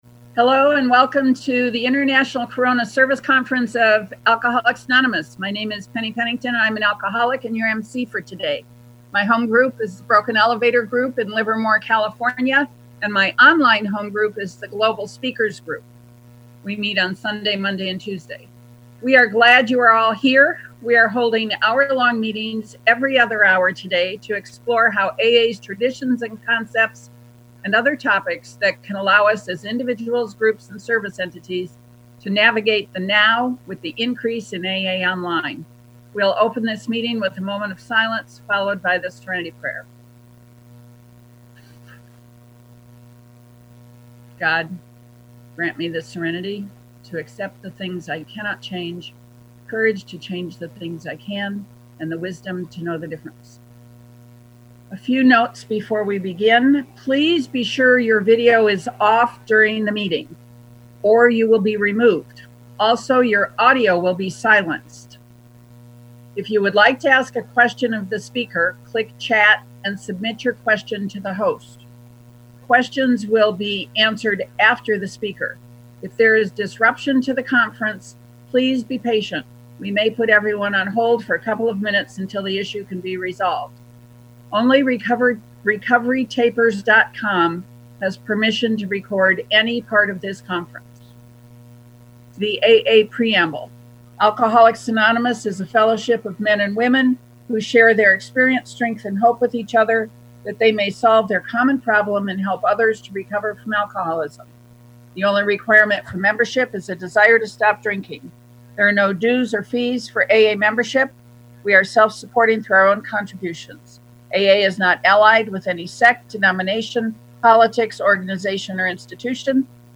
GA Traditions International Corona Service Conference of AA Worldwide 4-4-2020